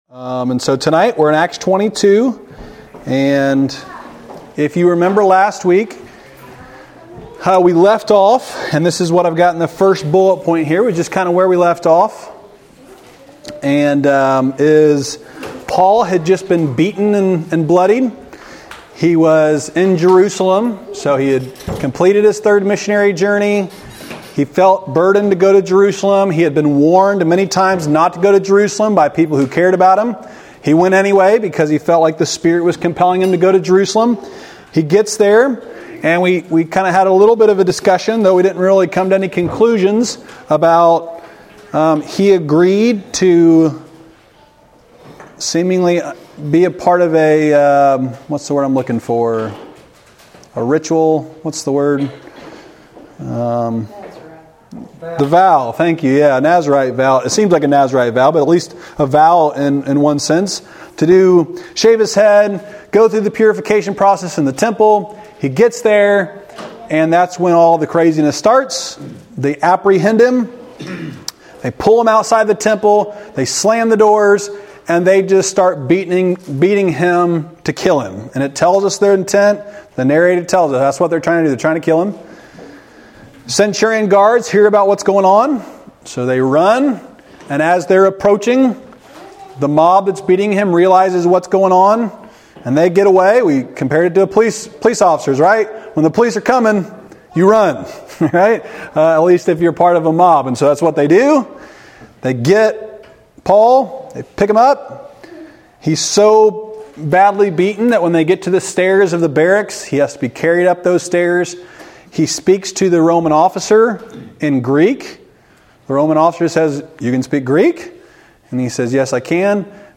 Wednesday night lesson from May 1, 2024 at Old Union Missionary Baptist Church in Bowling Green, Kentucky.